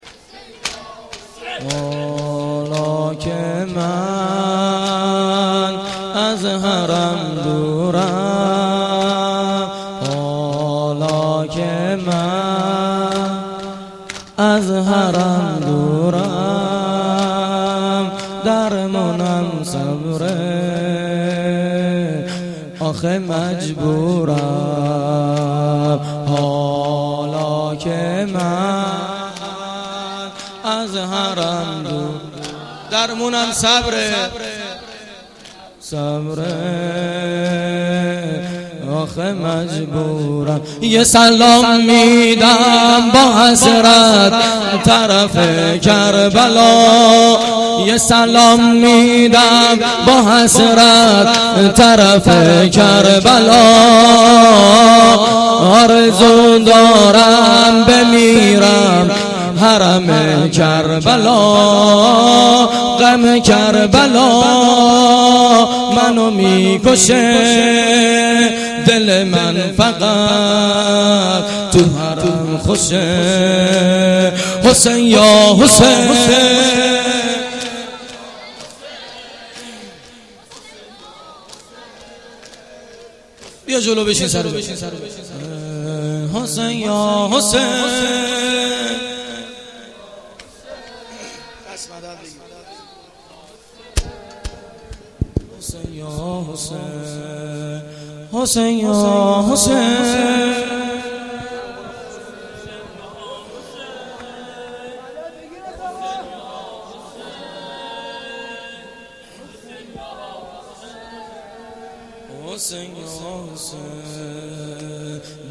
گزارش صوتی جلسه هفتگی2دیماه